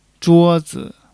zhuo1--zi.mp3